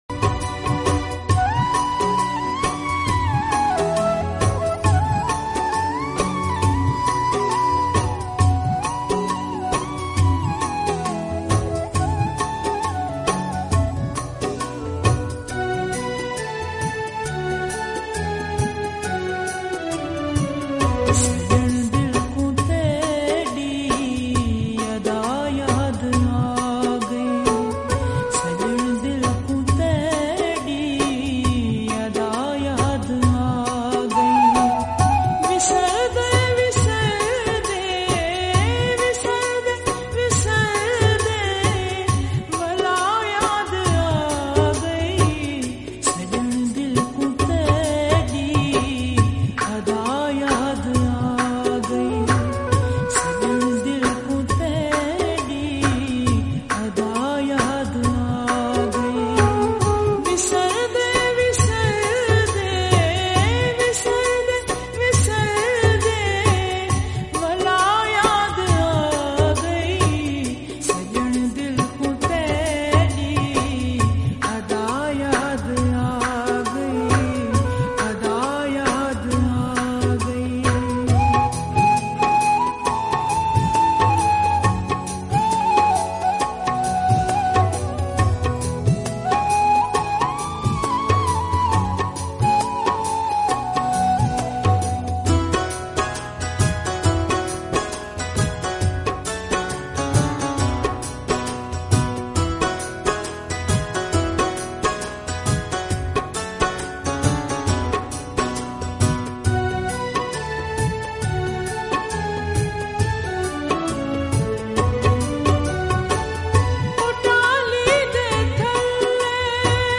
𝐒𝐚𝐫𝐚𝐢𝐤𝐢 𝐬𝐨𝐧𝐠
Slowed